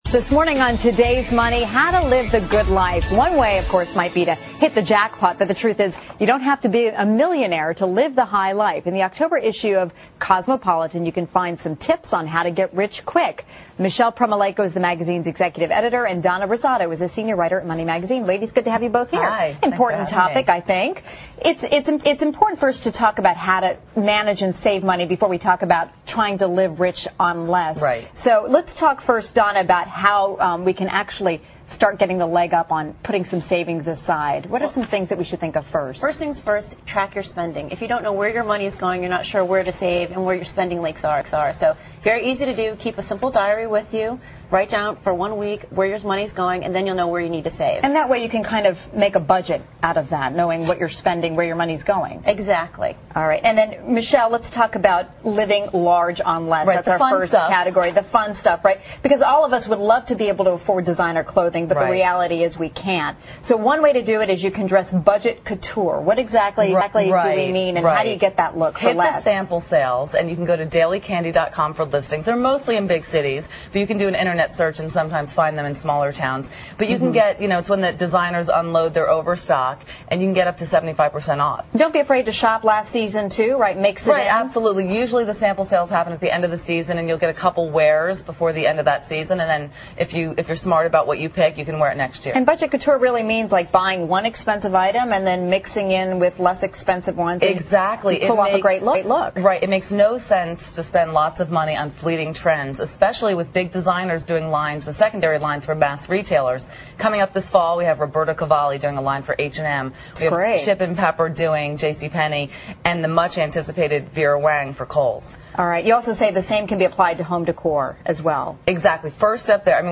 访谈录 Interview 2007-09-06&09-08, 怎样迅速变得富有？